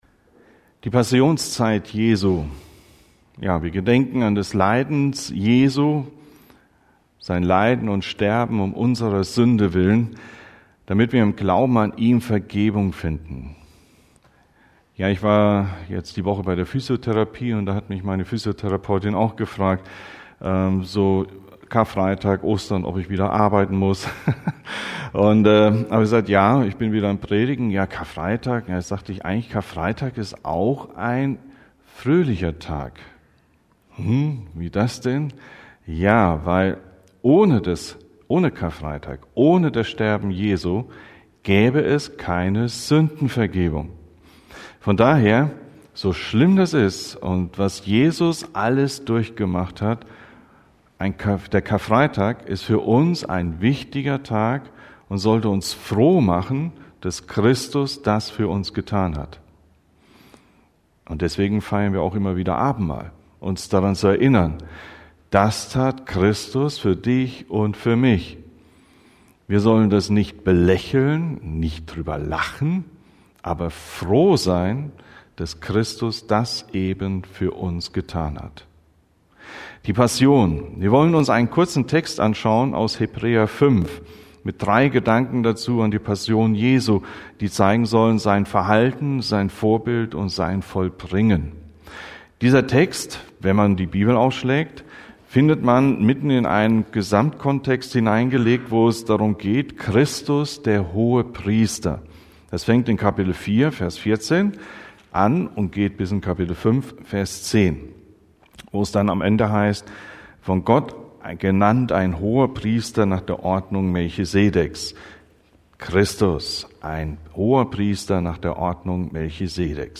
Die Passion Jesu – Predigten: Gemeinschaftsgemeinde Untermünkheim